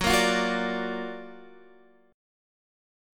F#+M7 chord